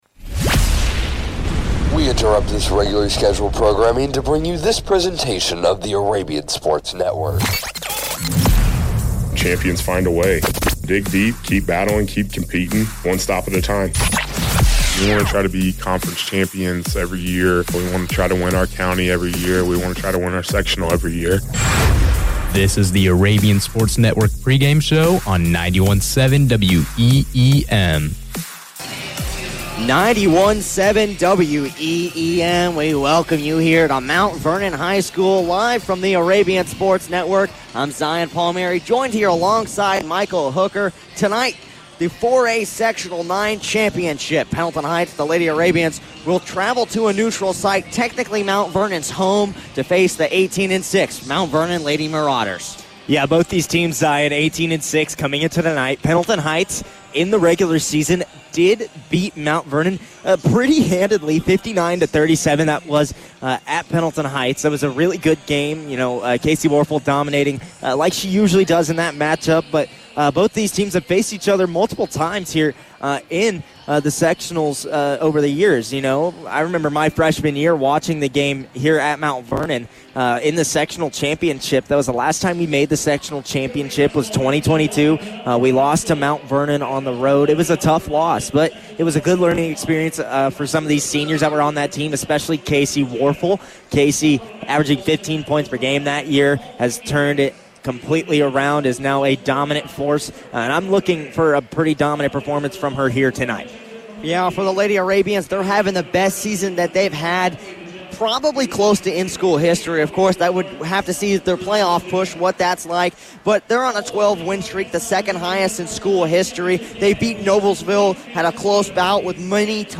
Varsity Girls Basketball Broadcast Replay Pendleton Heights vs. Mount Vernon SECTIONAL CHAMPIONSHIP 2-8-25